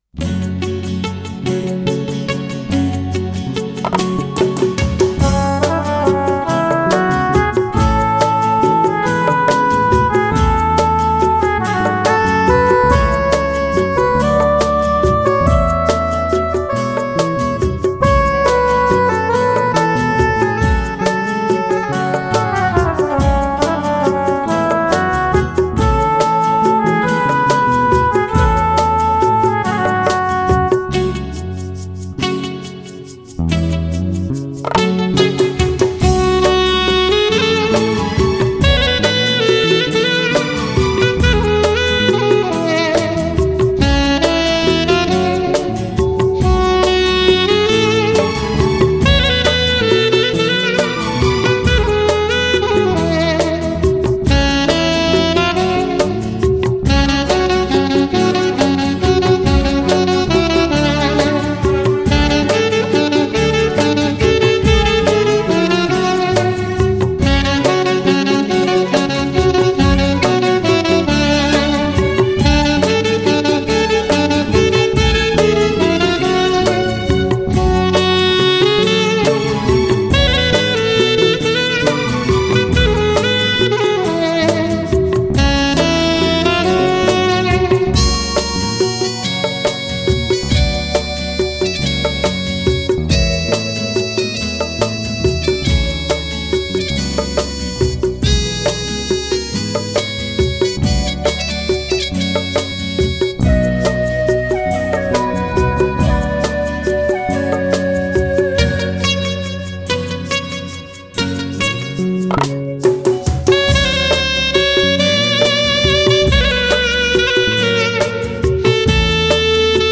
Category: Odia Karaoke instrumental Song